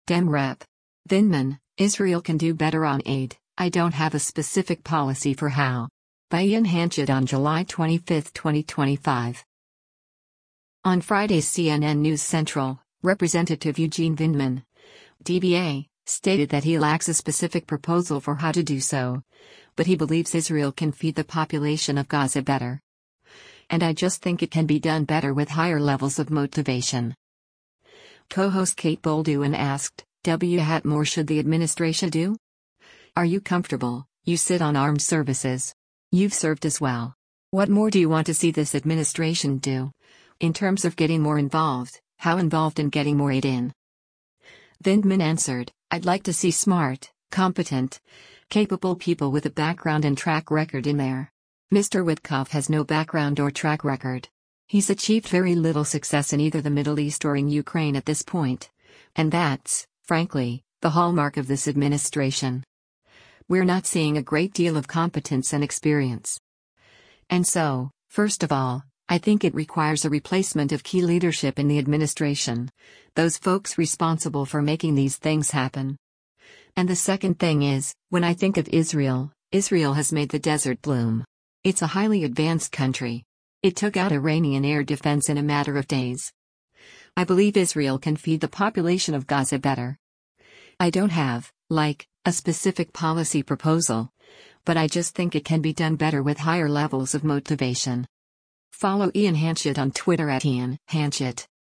On Friday’s “CNN News Central,” Rep. Eugene Vindman (D-VA) stated that he lacks a specific proposal for how to do so, but he believes “Israel can feed the population of Gaza better.” And “I just think it can be done better with higher levels of motivation.”
Co-host Kate Bolduan asked, “[W]hat more should the administration do?